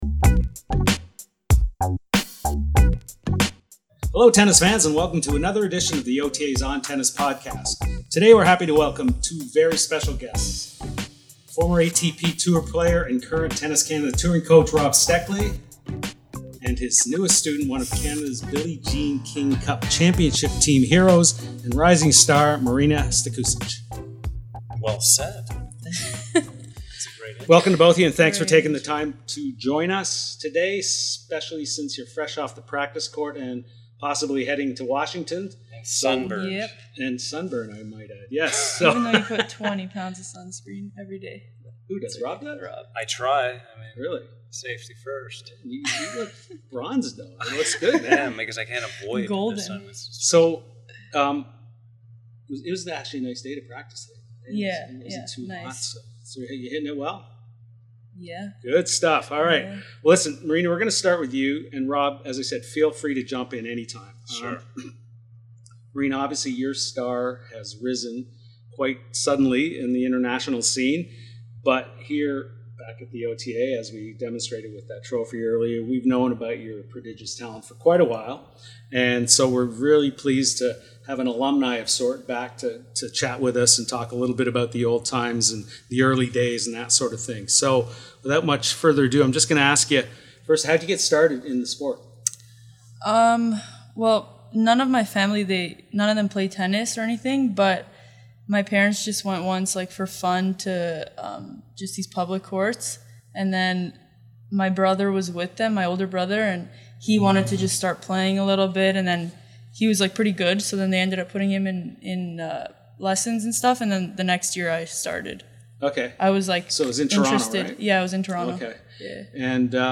ONTENNIS interview with Canada's rising star, Marina Stakusic ~ ONTENNIS Podcast